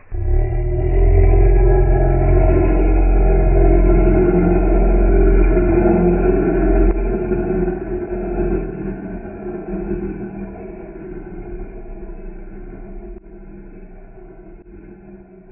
描述：经过处理的简短口音样本，在荷兰语中为'smeks'
标签： 关闭 效果 FX 怪物 怪物 加工 声乐
声道立体声